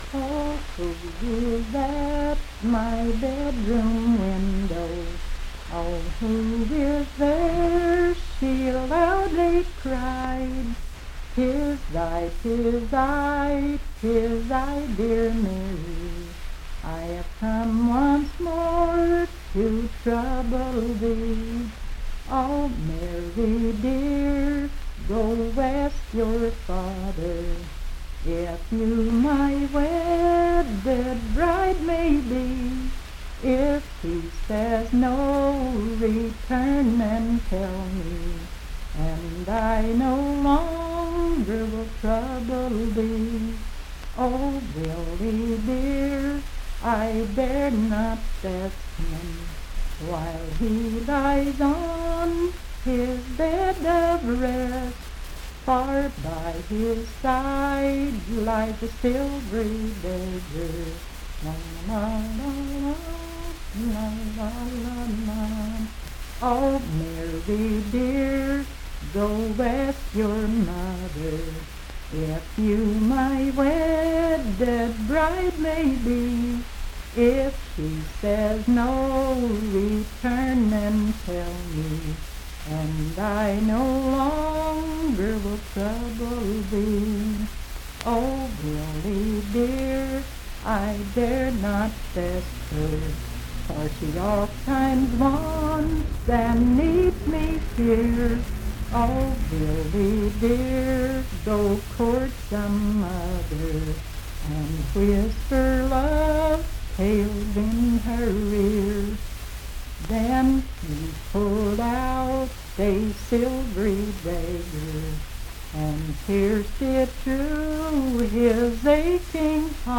Bedroom Window - West Virginia Folk Music | WVU Libraries
Unaccompanied vocal music
in Laurel Dale, W.V.
Voice (sung)
Mineral County (W. Va.)